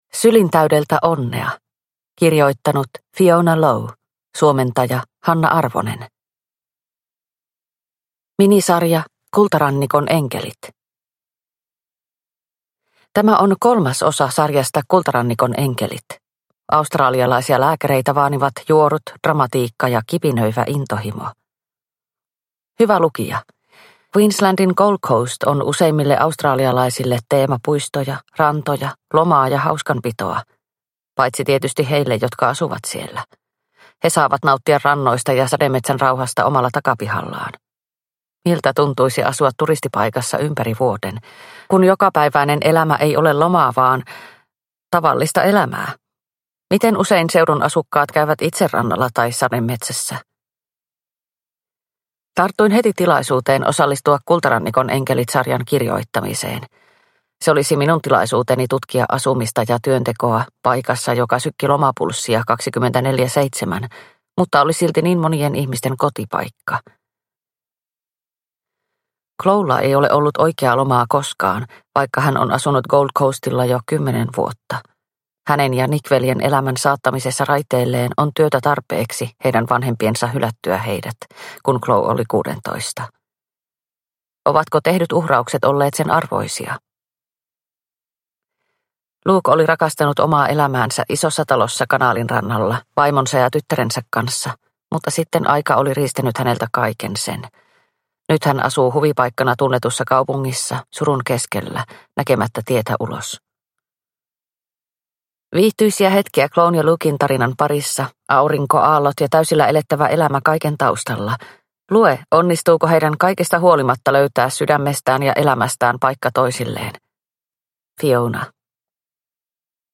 Sylin täydeltä onnea – Ljudbok – Laddas ner